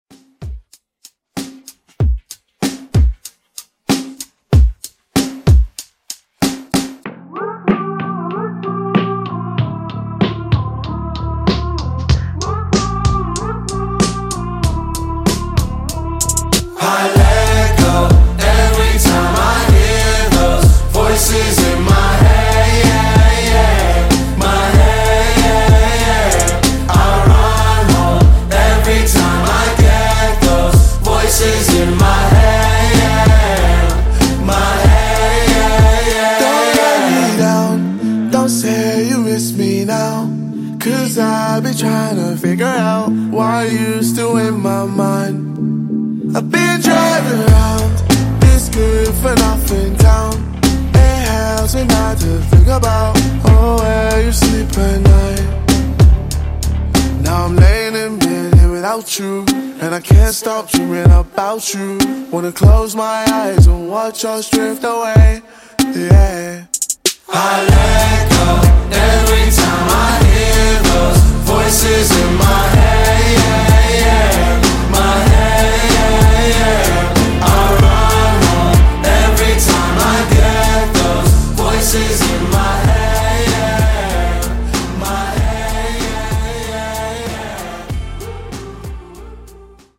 Genres: TECH HOUSE , TIK TOK HITZ , TOP40
Clean BPM: 128 Time